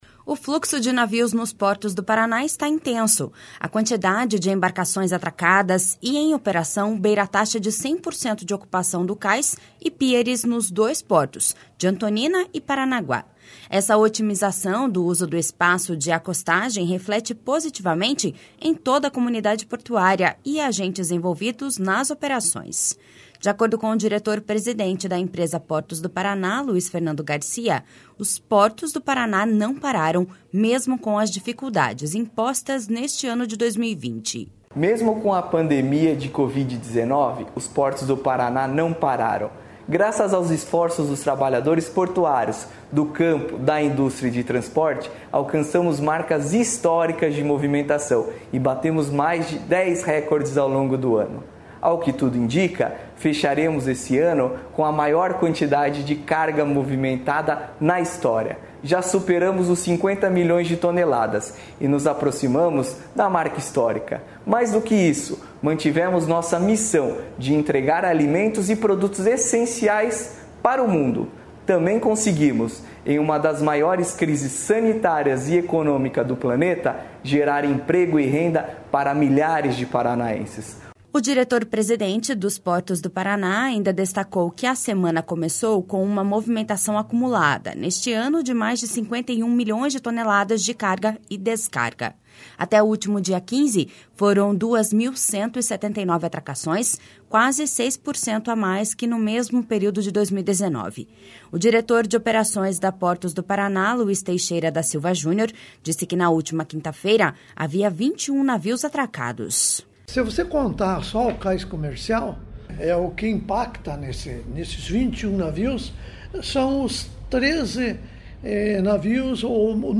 De acordo com o diretor-presidente da empresa Portos do Paraná, Luiz Fernando Garcia, os portos do Paraná não pararam, mesmo com as dificuldades impostas neste ano de 2020.// SONORA LUIZ FERNANDO GARCIA.//